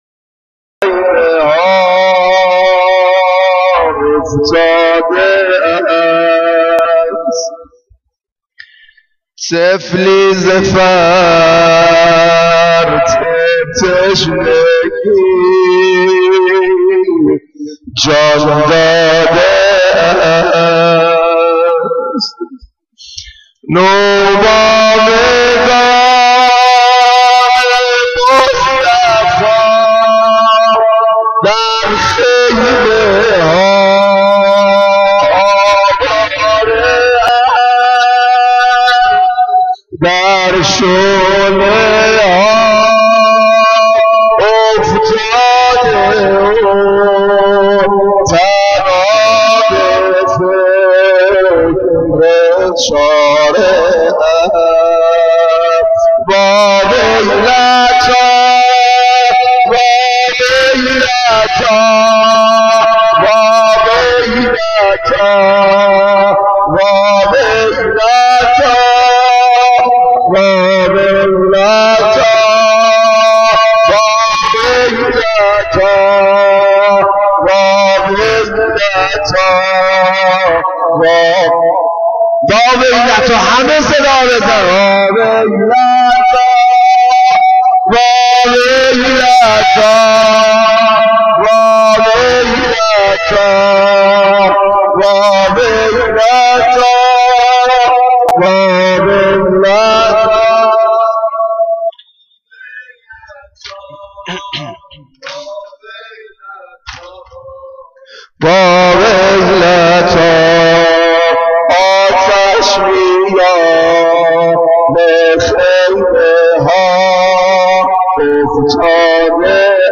سینه زنی 11.wma